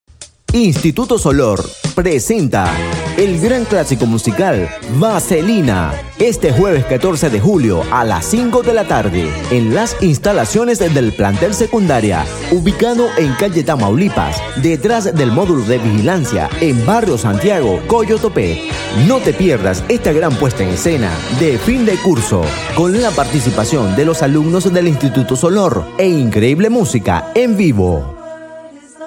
Cuña para el Instituto Solort evento de fin de curso: Vaselina